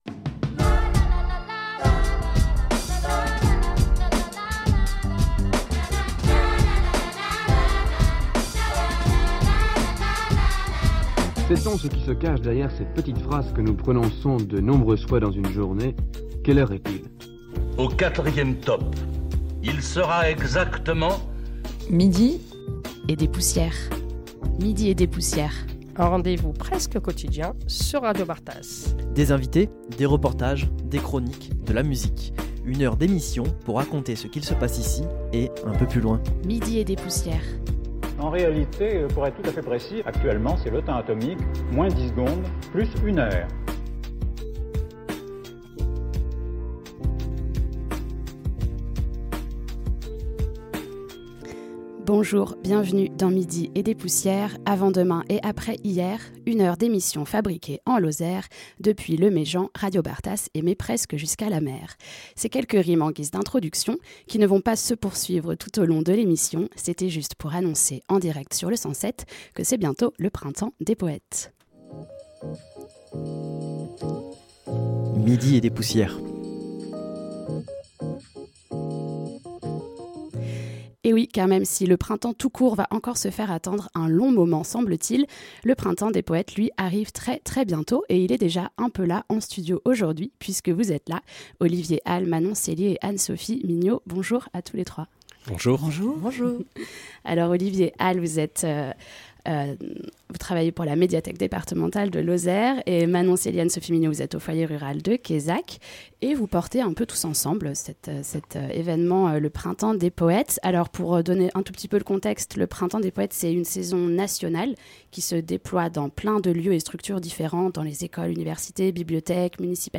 Le printemps des poètes se raconte en direct sur le 107